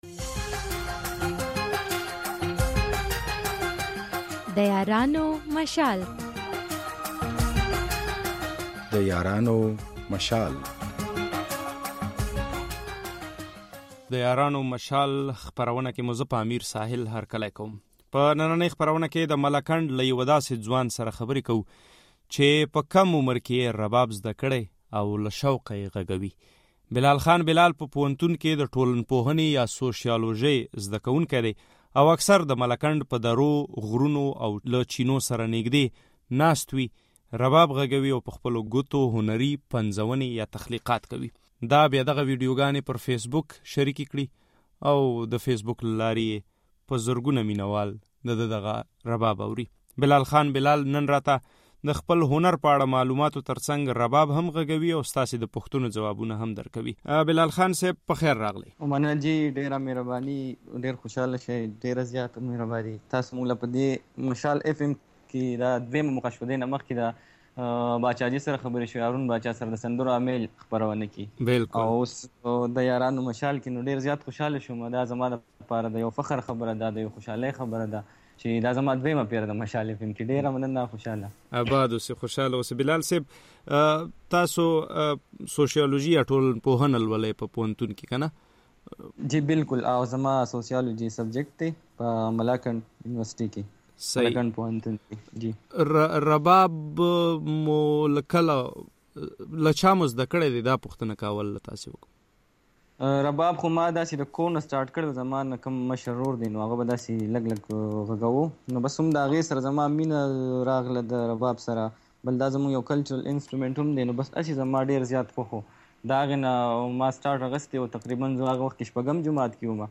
د رباب نغمې